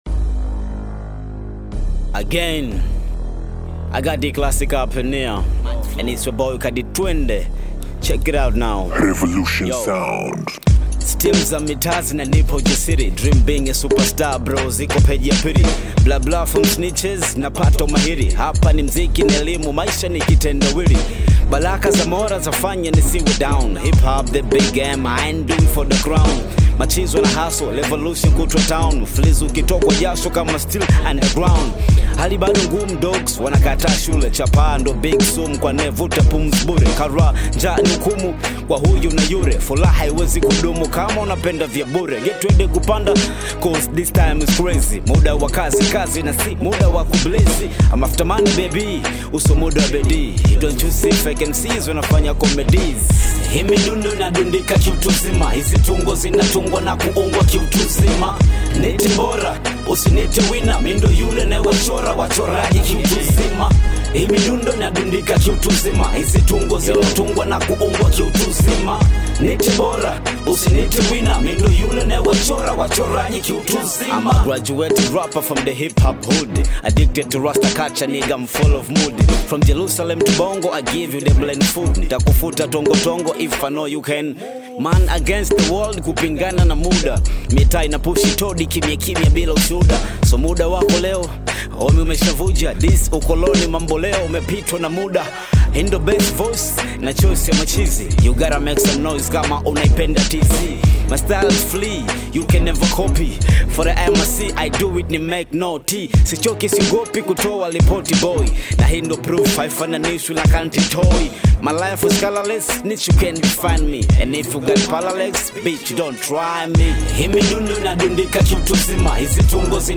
Ameshikikia mizani na vina